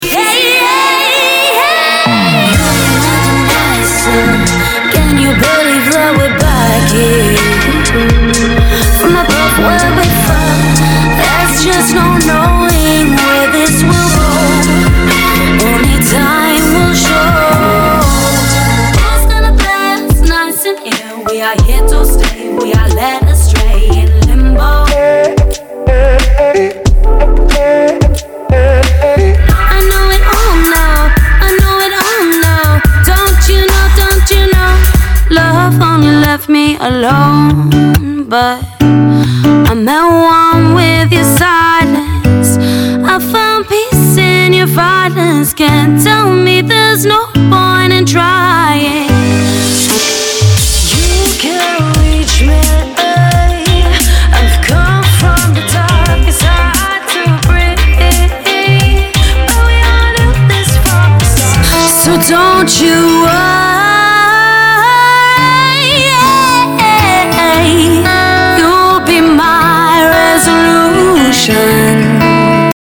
Female
British English (Native)
Singing shorts.mp3
Microphone: Rode NT2-A, Shure SM7B, Shure SM58